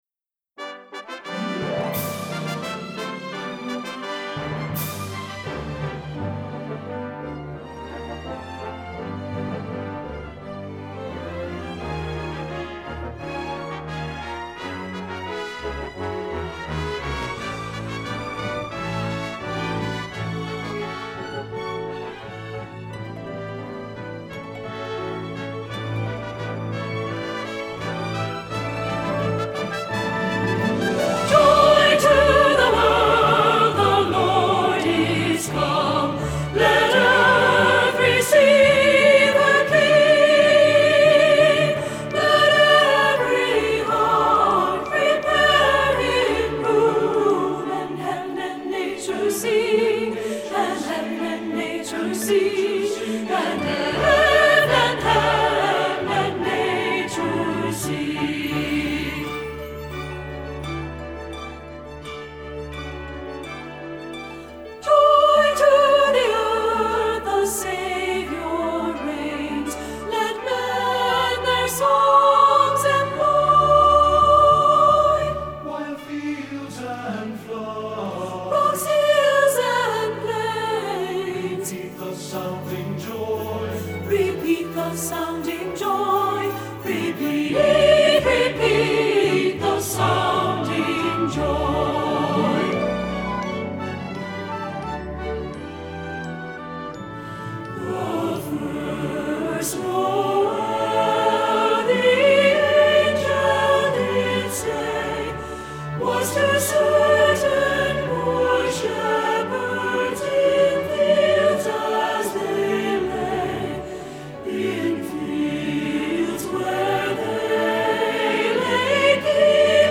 Choir Music | Belin Memorial UMC
Soprano: